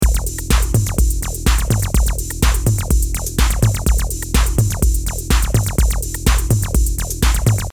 все синты были сделаны в Serum
Не силён в терминологии как звук называется, там такой пиу-пиу есть, как лазер, и как водичка что-ли, он сделан был из какого-то acid баса (вроде как), скорее всего сверху лежали эффекты типа какого-то дисторшна из набора d16 Group. больше ничего не помню. Пытаюсь восстановить и всё получается как кислотный бас, такой рельефный пилообразный, а искомый звук более мягкий.